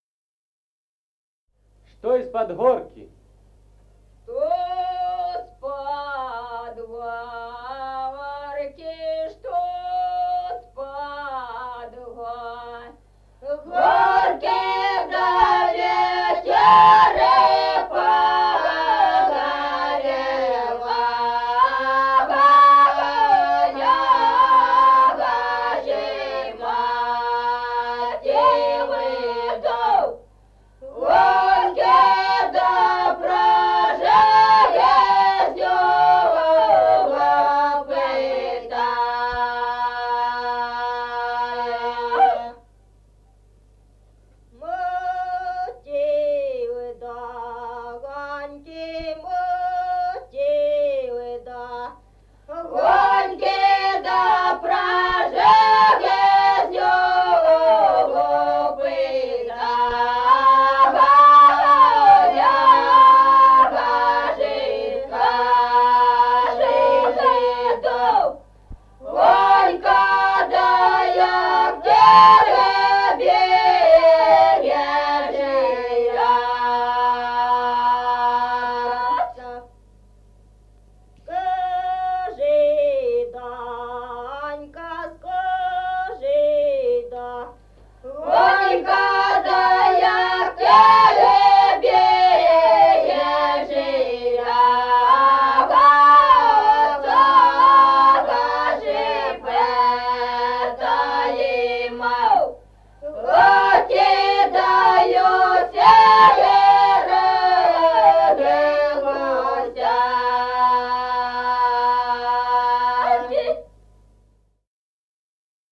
Музыкальный фольклор села Мишковка «Что с-под горки», лирическая.